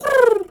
pgs/Assets/Audio/Animal_Impersonations/pigeon_2_call_02.wav at master
pigeon_2_call_02.wav